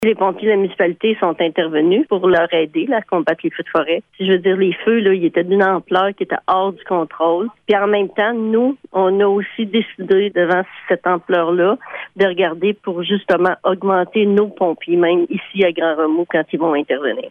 À ce propos, la mairesse Jocelyne Lyrette :